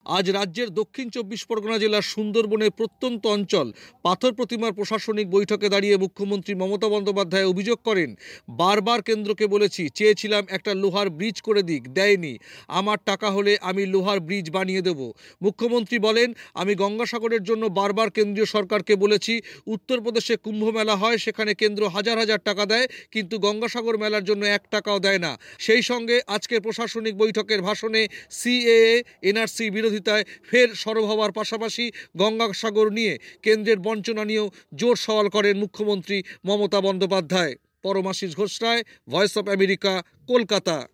পশ্চিমবঙ্গের দক্ষিণ 24 পরগনা জেলার সুন্দরবন এলাকার প্রত্যন্ত পাথরপ্রতিমায় প্রশাসনিক সভা থেকে গঙ্গাসাগর মেলার প্রতি কেন্দ্রের বঞ্চনার অভিযোগে সরব হলেন মুখ্যমন্ত্রী মমতা বন্দ্যোপাধ্যায়।